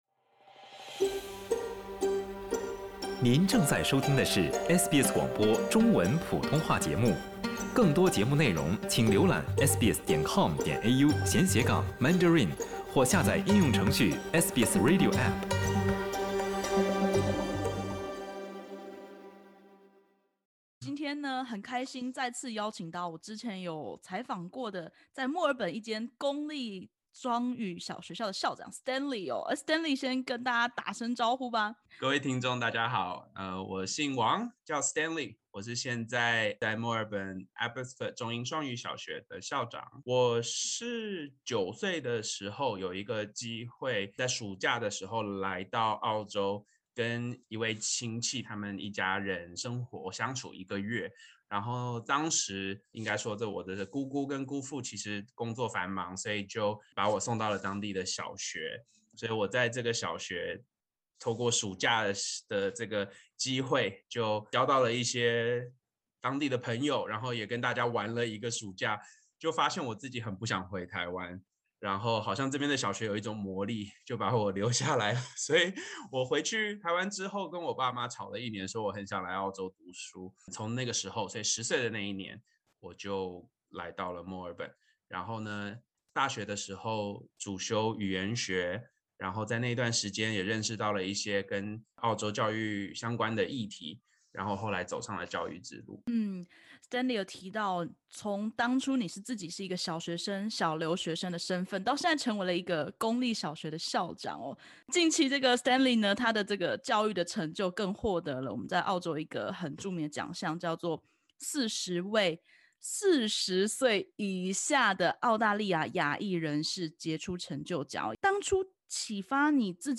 【专访】